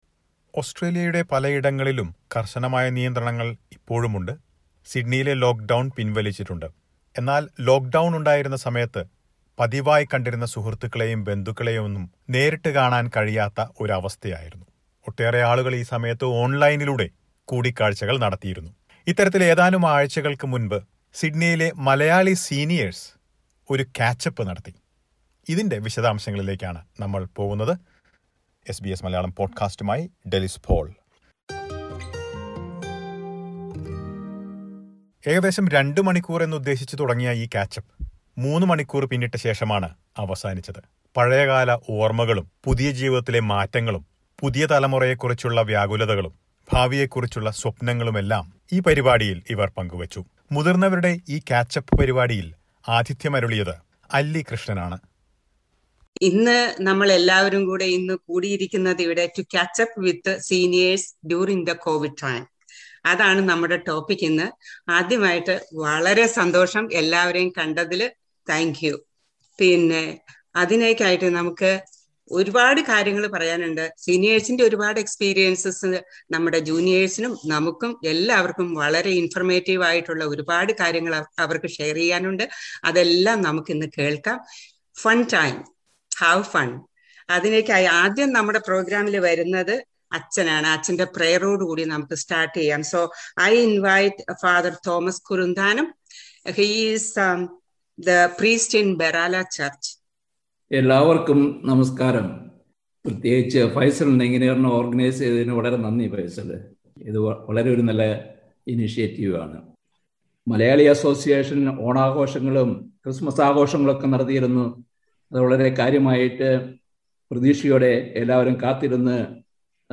Sydney Malayalee seniors catch up online during lockdown: report